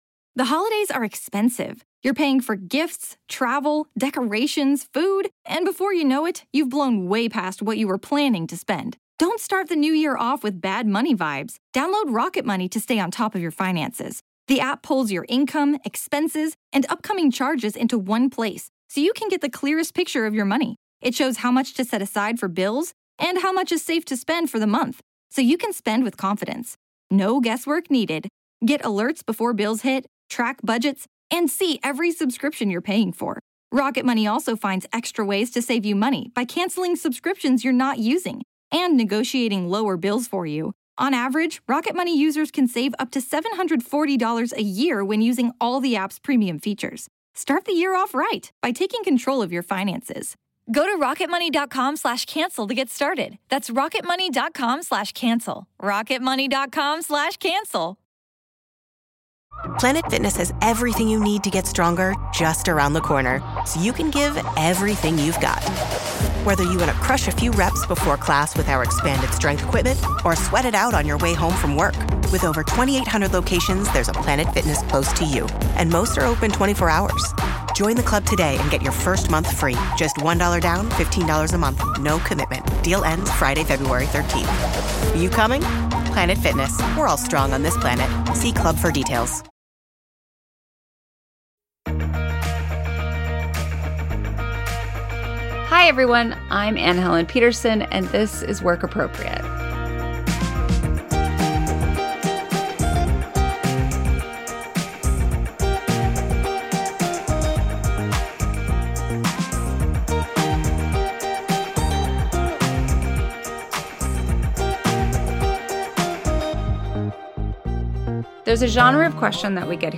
Josh Gondelman, our first three-peat guest, joins host Anne Helen Petersen to answer questions from listeners who feel woefully, hopelessly stuck in their jobs.